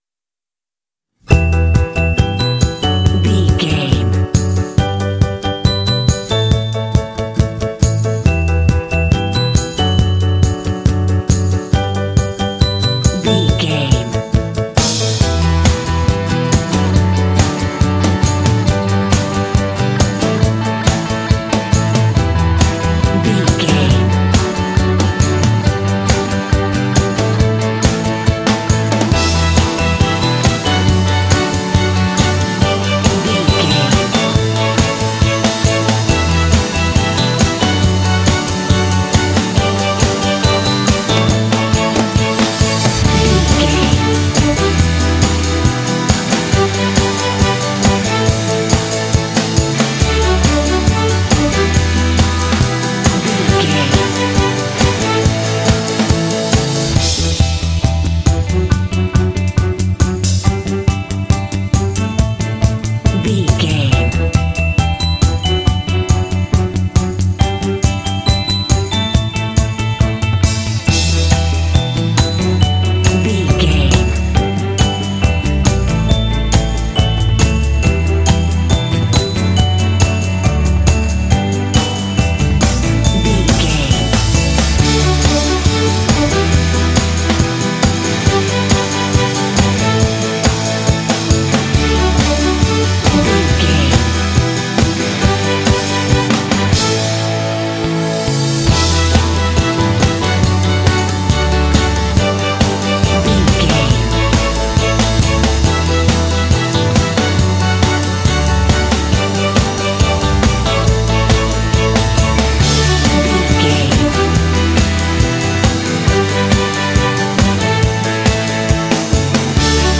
Fun and cheerful indie track with bells.
Uplifting
Ionian/Major
D
optimistic
bright
piano
bass guitar
drums
electric guitar
strings
pop
symphonic rock